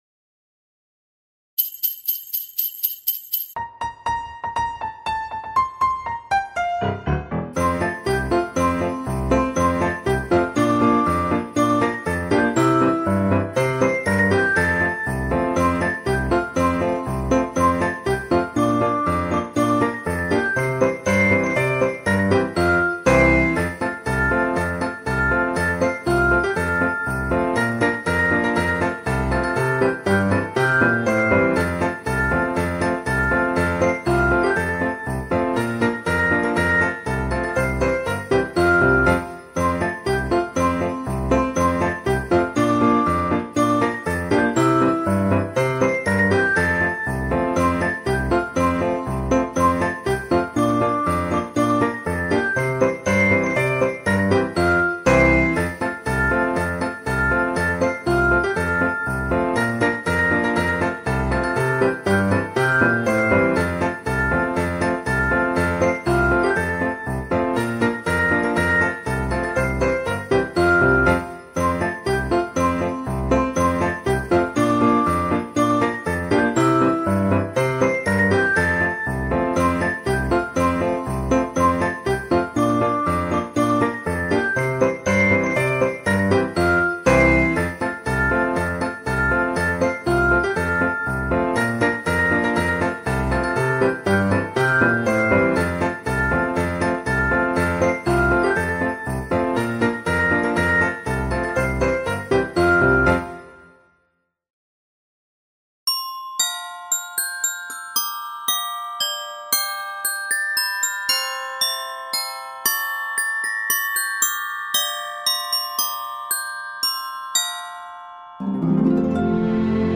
BONUS-Ambiance-Musiques-de-Noel-30-Minutes.mp3